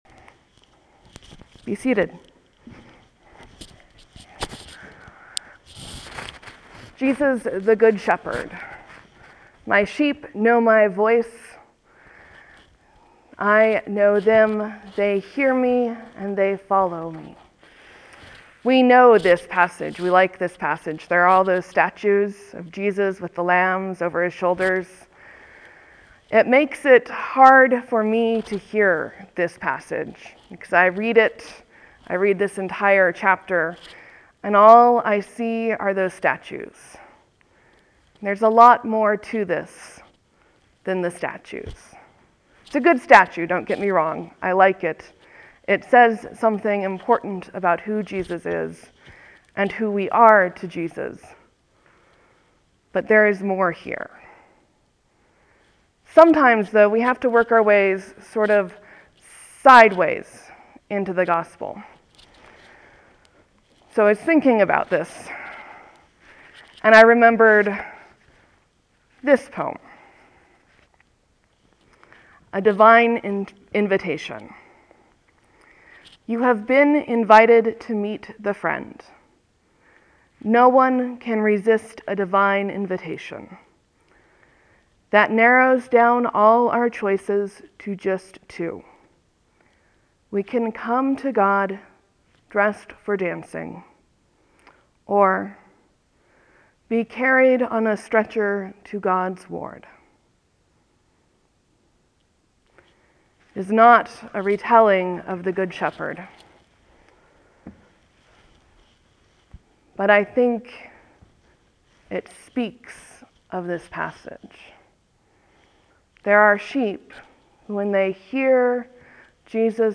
Dancing, Stretchers, and Sheep, a sermon for Easter 4 2013